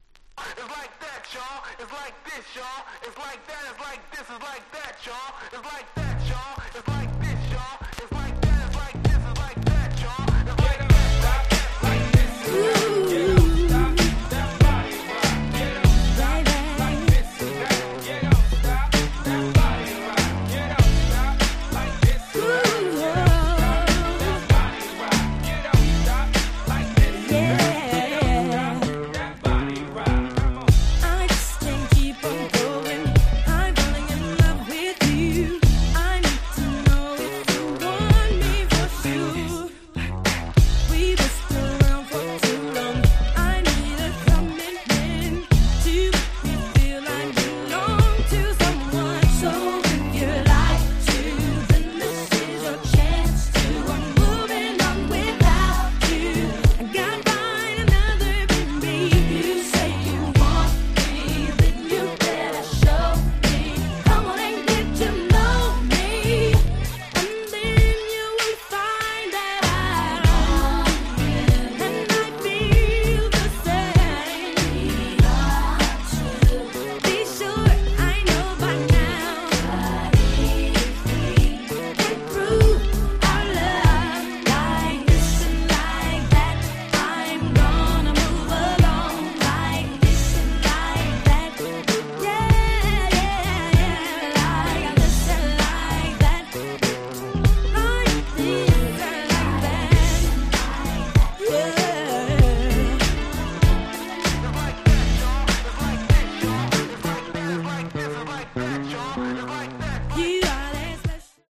(試聴ファイルは別の盤から録音しております。)